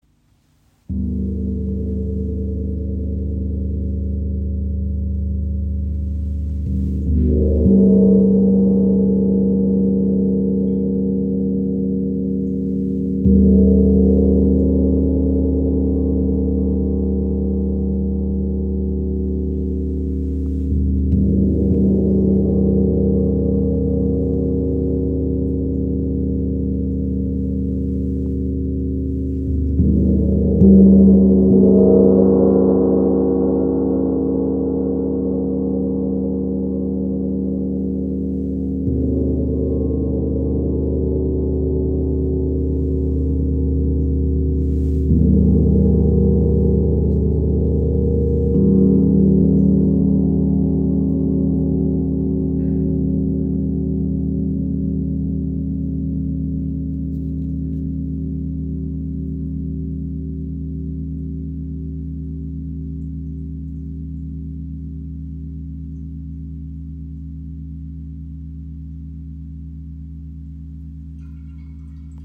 Klangbeispiel
Sein Klang ist tief, ausgewogen und reich an Obertönen, die eine Atmosphäre zwischen Erdentiefe und kosmischer Weite erschaffen. Mit Reibungsschlägeln wie den B Love Flumies entstehen sanfte, traumhafte Wal- und Delfinsounds. Ideal für Meditation, Rituale, Klangreisen und ganzheitliche Therapie.